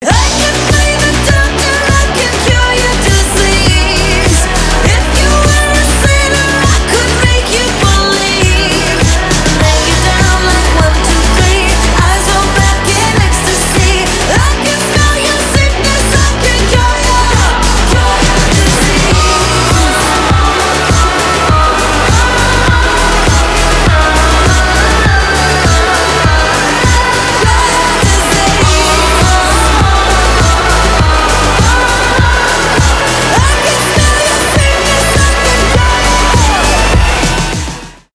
танцевальные
электропоп , пианино , качающие
зажигательные , нарастающие , битовые , басы